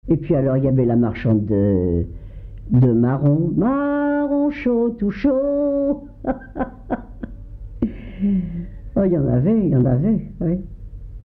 chants brefs - cris de rue
Enquête dans les Résidences de personnes âgées du Havre
Pièce musicale inédite